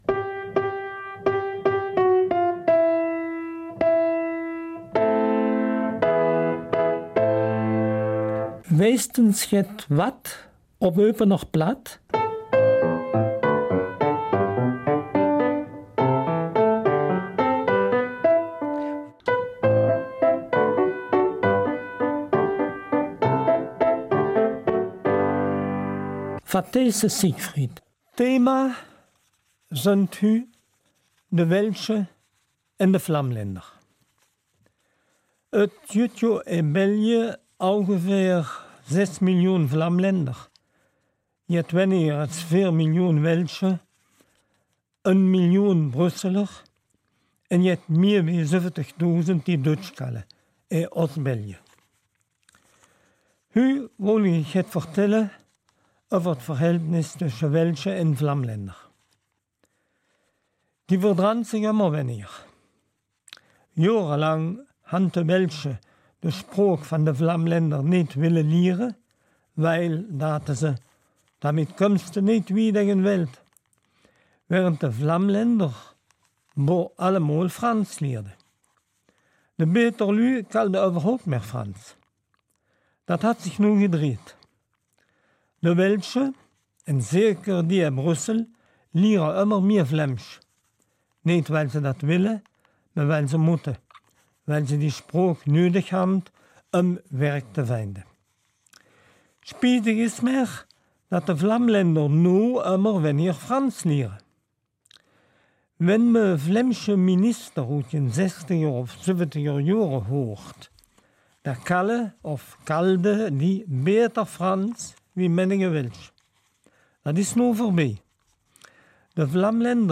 Eupener Mundart - 13. Oktober